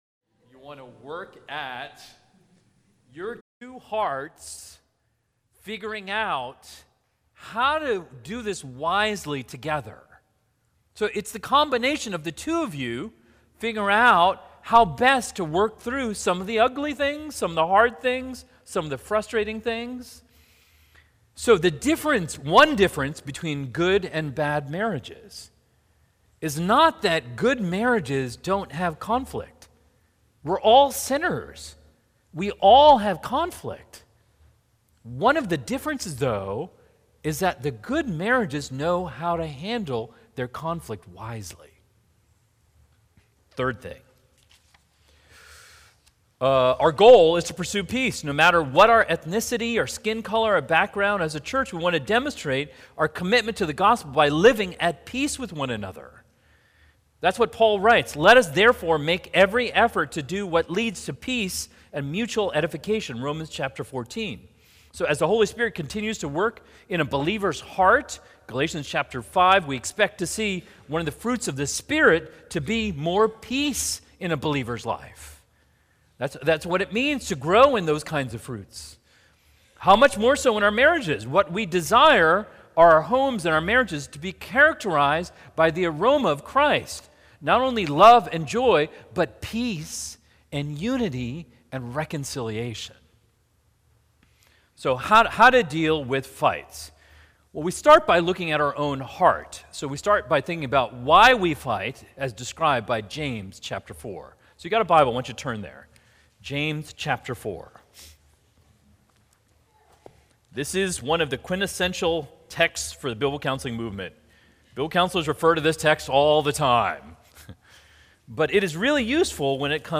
Audio recorded at the 2022 MVBC Marriage Conference.